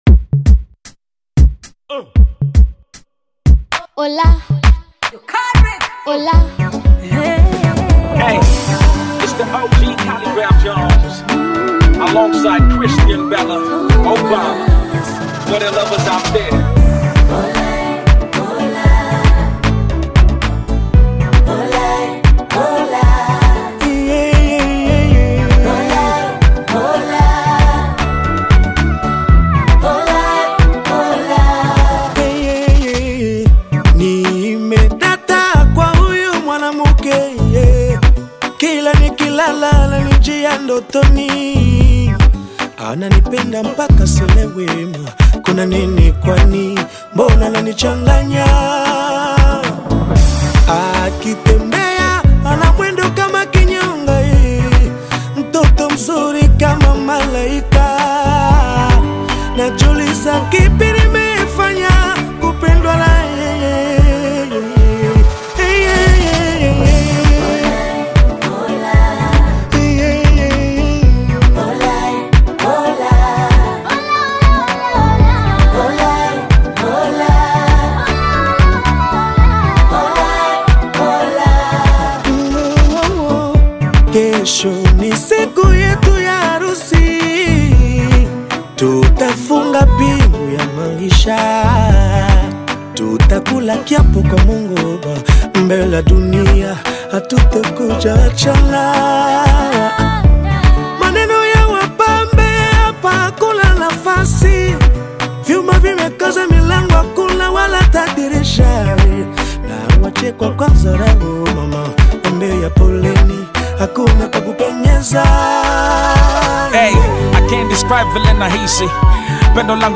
single
Hip hop